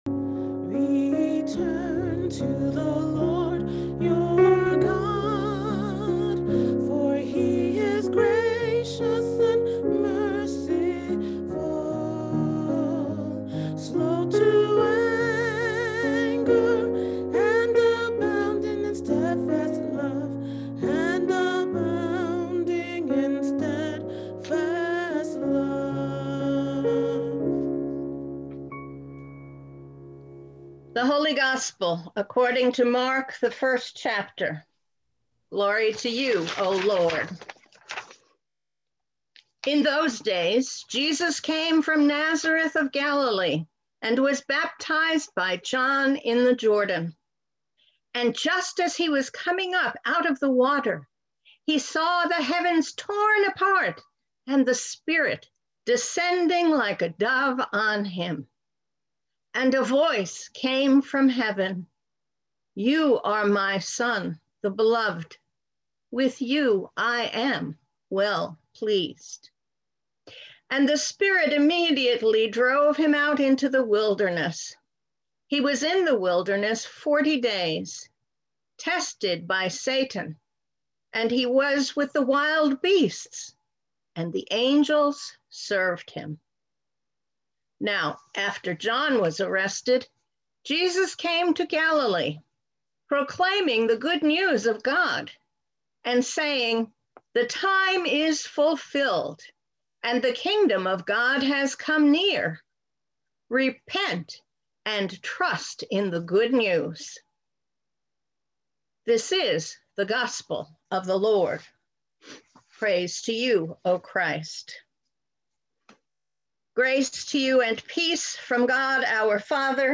Sermons | Lutheran Church of the Epiphany and Iglesia Luterana de la Epifania